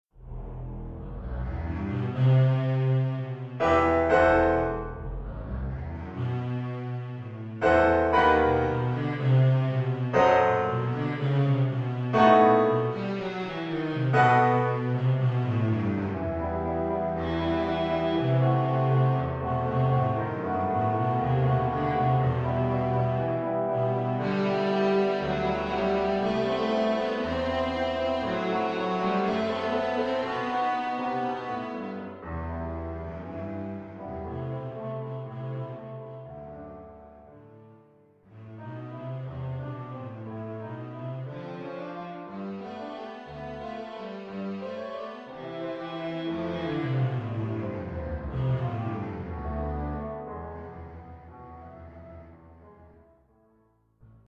Oeuvre pour contrebasse
avec accompagnement de piano.